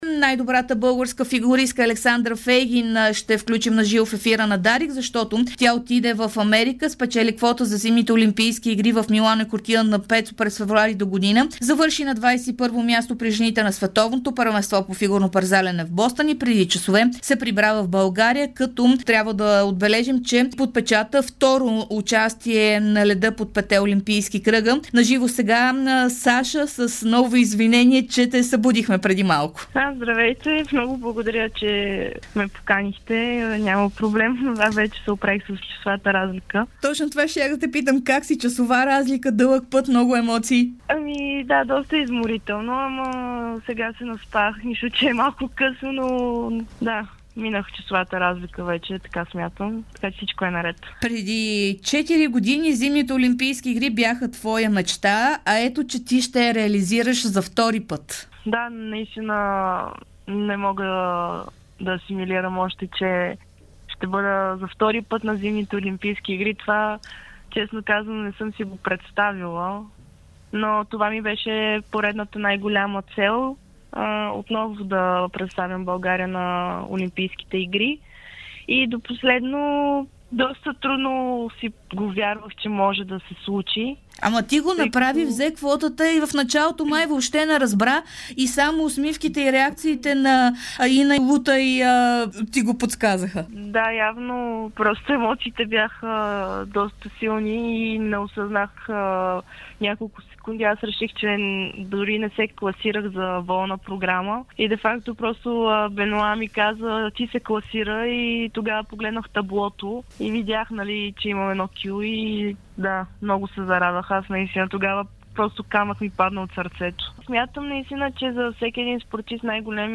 Най-добрата българска фигуристка – Александра Фейгин, даде интервю за Дарик радио и dsport, след като спечели квота за зимните Олимпийски игри Милано и Кортина д'Ампецо през 2026 година.